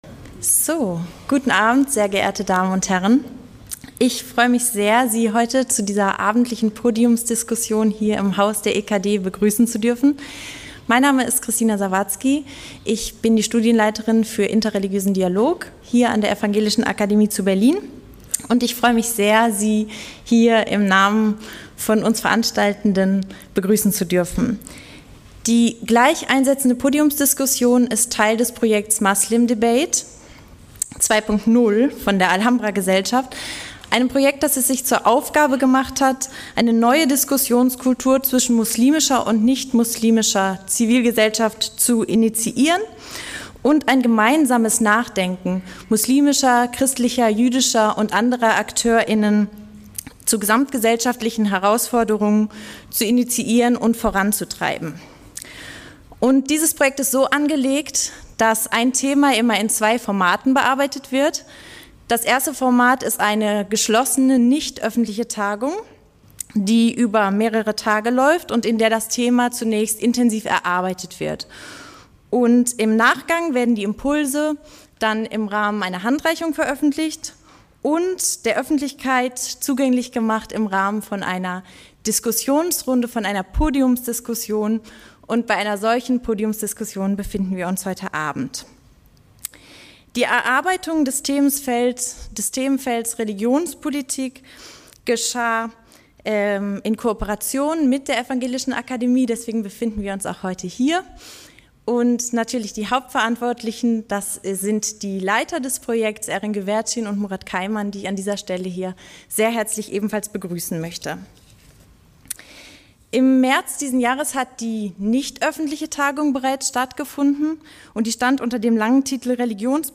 Podiumsdiskussion über die religionspolitische Erschöpfung und die akuten Herausforderungen Podiumsteilnehmer: – Lamya Kaddor, MdB und religionspolitische Sprecherin von Bündnis90/Die Grünen – Jens Spahn, MdB und stellvertretender Fraktionsvorsitzender vo...